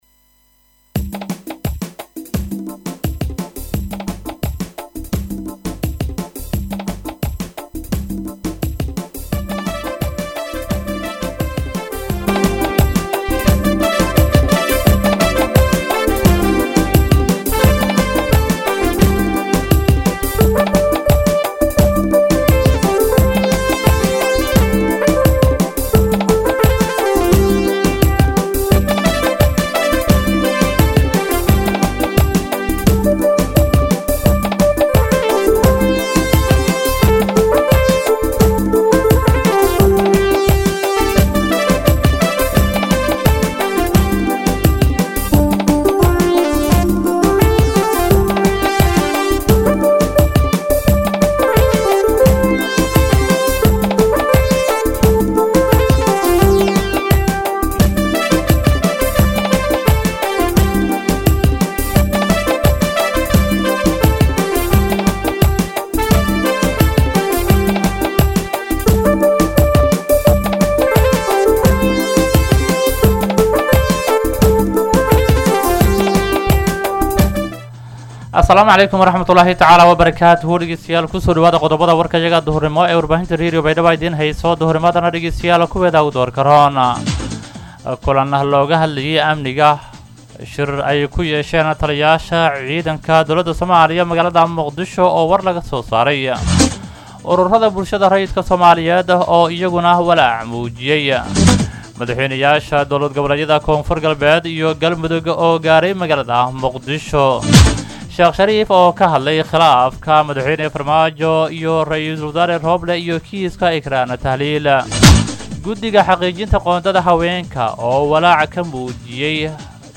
DHAGEYSO:-Warka Duhurnimo Radio Baidoa 9-9-2021